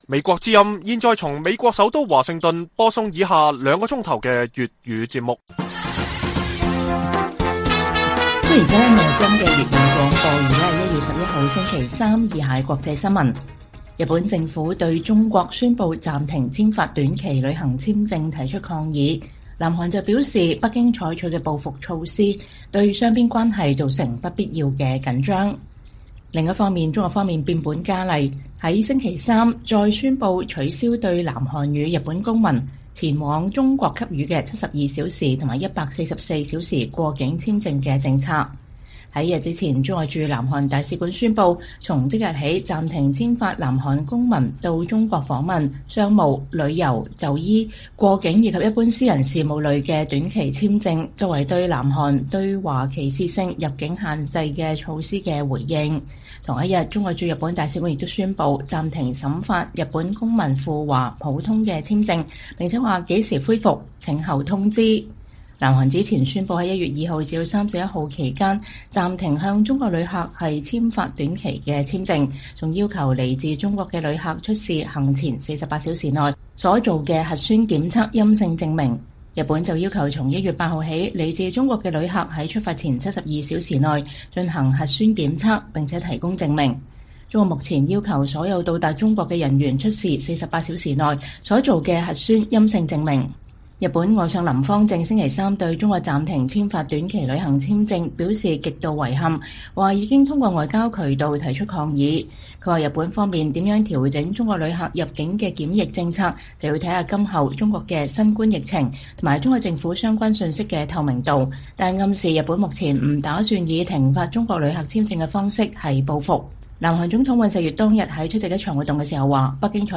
粵語新聞 晚上9-10點: 美眾院通過決議成立“美國與中共戰略競爭特設委員會”